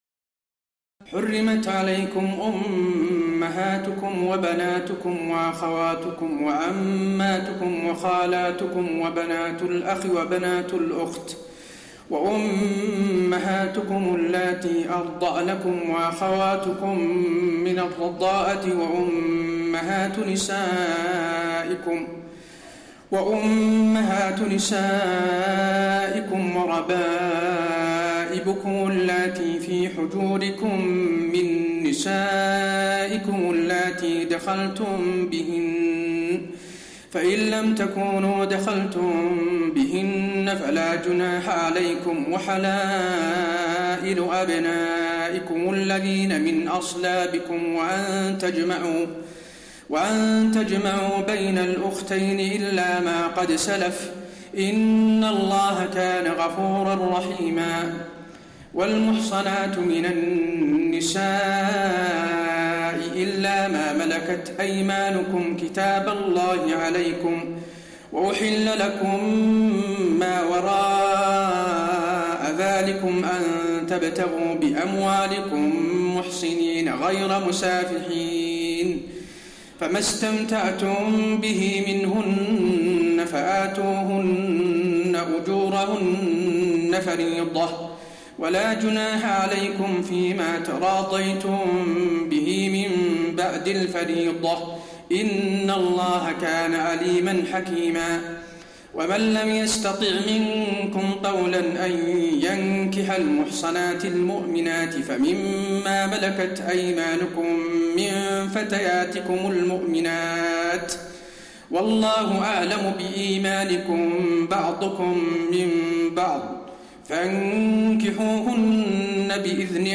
تراويح الليلة الخامسة رمضان 1432هـ من سورة النساء (23-87) Taraweeh 5 st night Ramadan 1432H from Surah An-Nisaa > تراويح الحرم النبوي عام 1432 🕌 > التراويح - تلاوات الحرمين